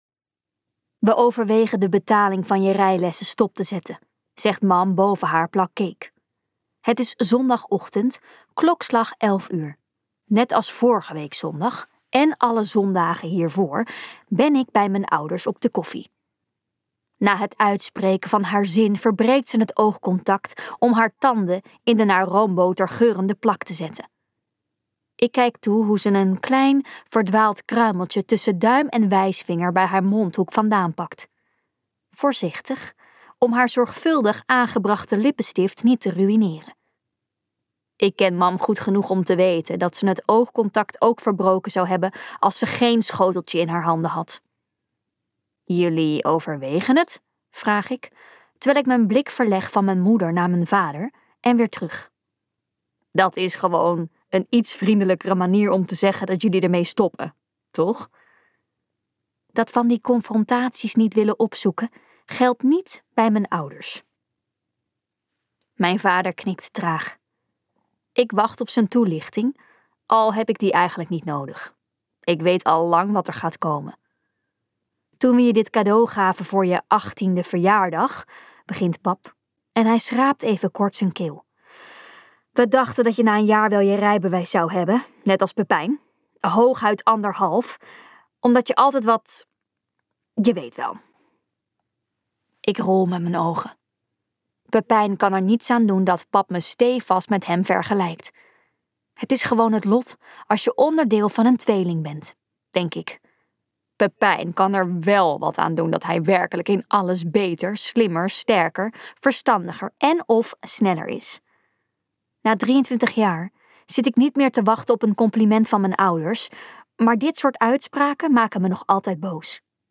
Ambo|Anthos uitgevers - Amber emir luisterboek